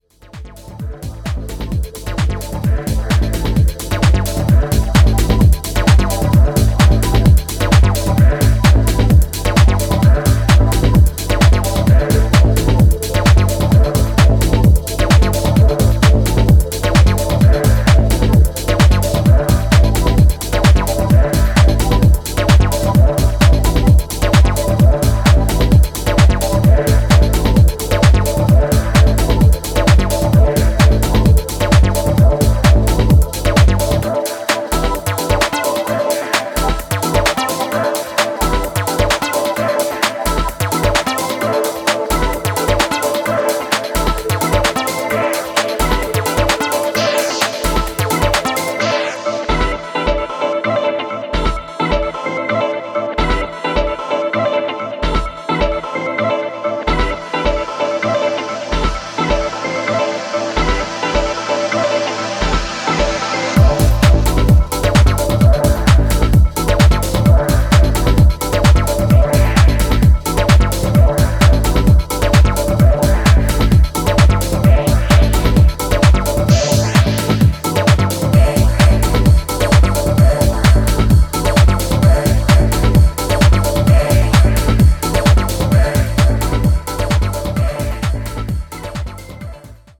Deep dubby techno and upbeat house tracks with a dubby feel
Techno Bass Dub Techno